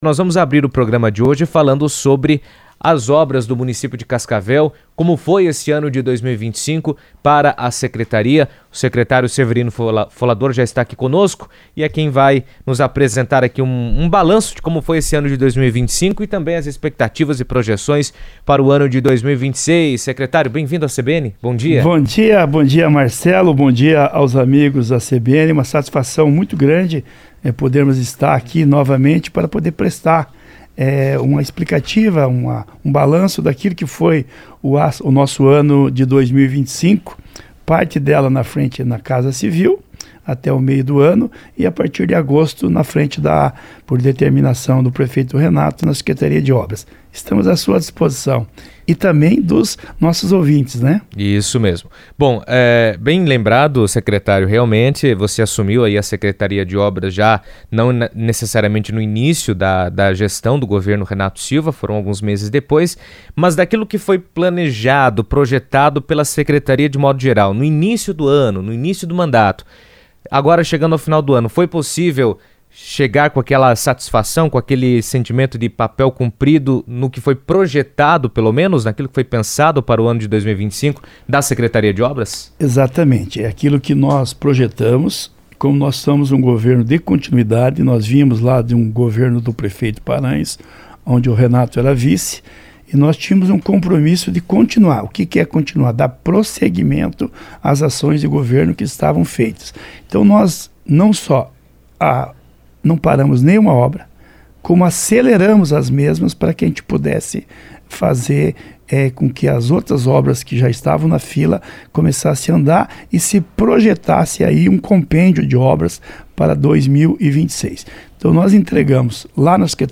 A Secretaria de Obras de Cascavel apresentou um balanço das intervenções realizadas em 2025 e detalhou os projetos previstos para 2026. Durante a apresentação, foram destacadas as obras concluídas e os desafios que a cidade ainda enfrenta, como a manutenção de ruas com buracos. O secretário de Obras, Severino Folador, comentou sobre o assunto em entrevista à CBN, abordando o planejamento das ações para o próximo ano.